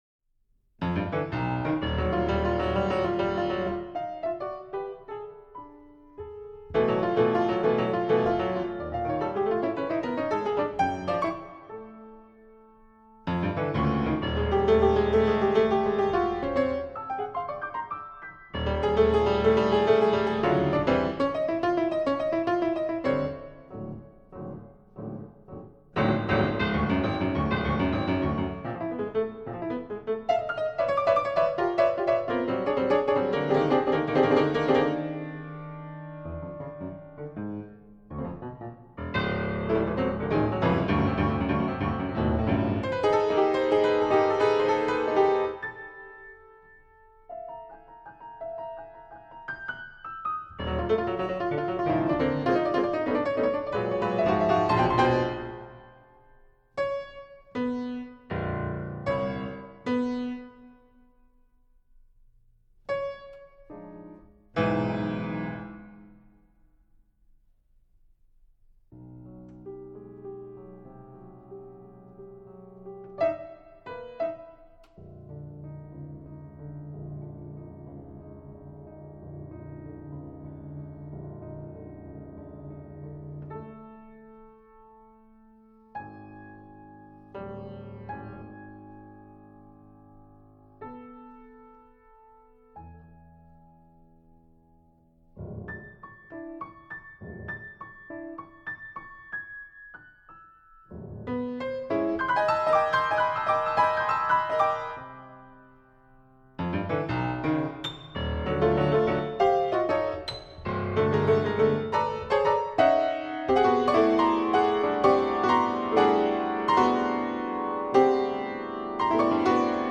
Skip, for solo piano